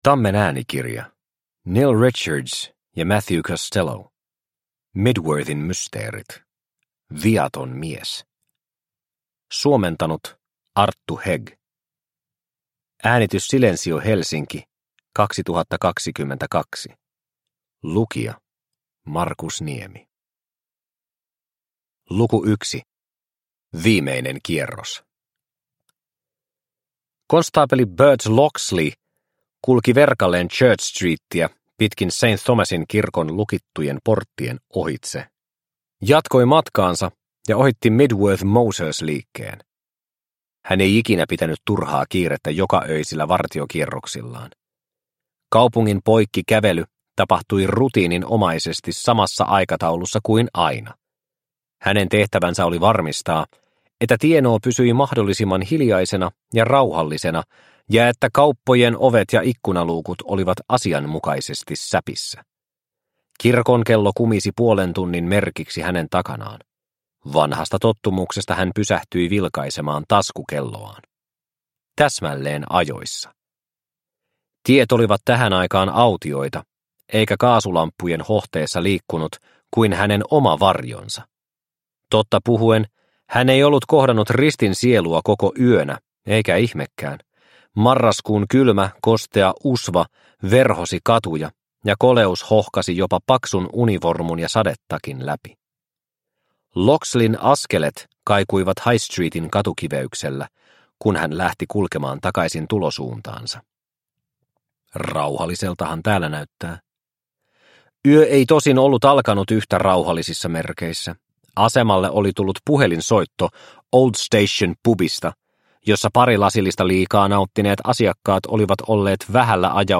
Mydworthin mysteerit: Viaton mies – Ljudbok – Laddas ner